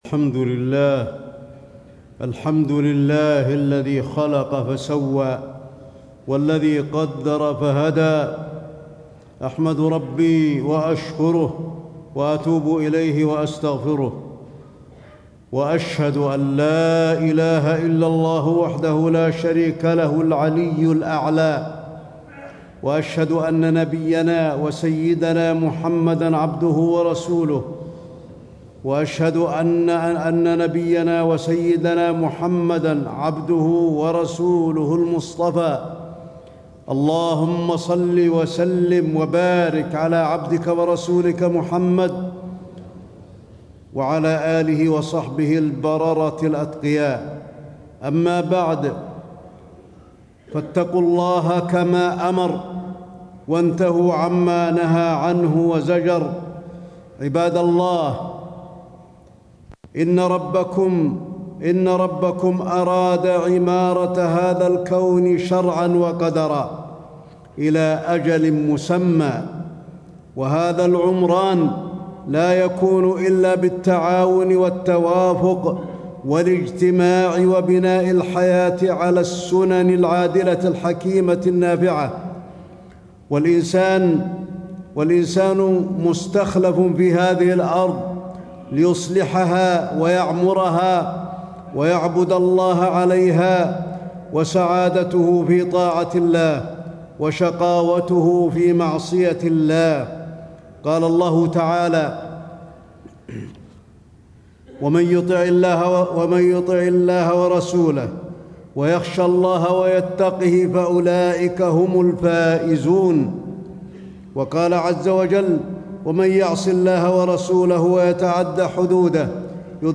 تاريخ النشر ٨ جمادى الأولى ١٤٣٦ هـ المكان: المسجد النبوي الشيخ: فضيلة الشيخ د. علي بن عبدالرحمن الحذيفي فضيلة الشيخ د. علي بن عبدالرحمن الحذيفي الزواج فضائل وفوائد وأحكام